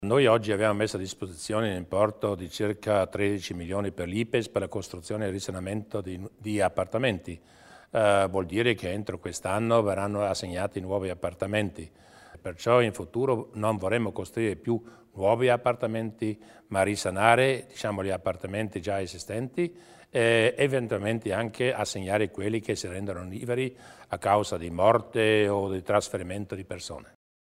Luis Durnwalder illustra i dettagli degli investimenti a favore dell'IPES